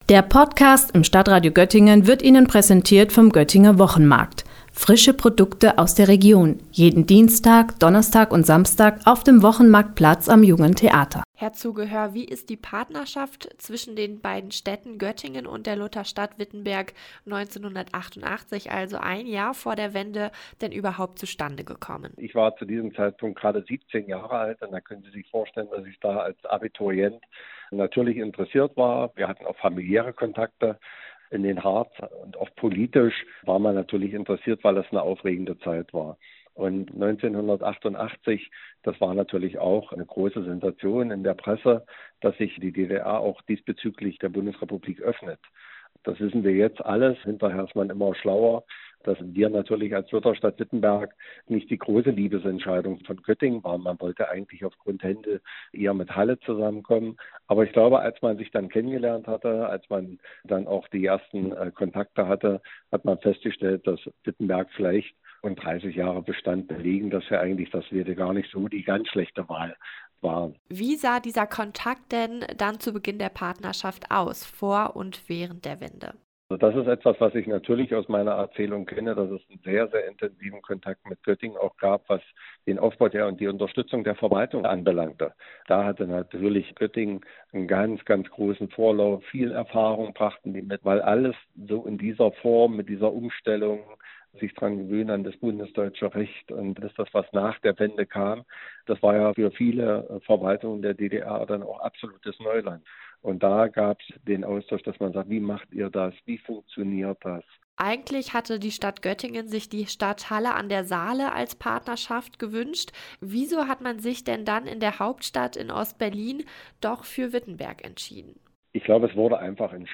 Einer, der die Grenzöffnung als Jugendlicher in Witttenberg miterlebt hat, ist Torsten Zugehör, inzwischen Oberbürgermeister der Lutherstadt Wittenberg. Für ihn kam die Wende genau zum richtigen Zeitpunkt.